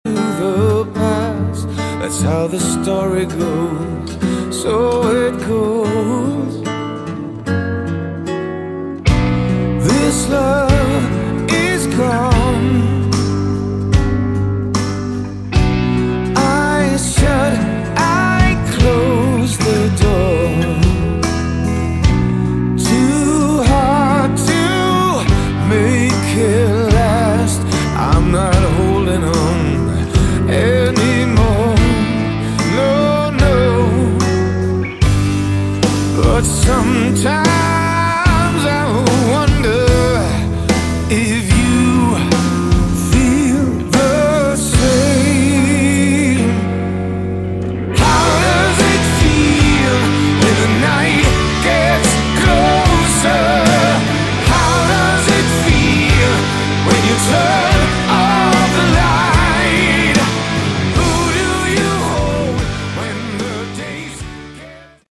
Category: Melodic Rock
vocals, guitar
bass
drums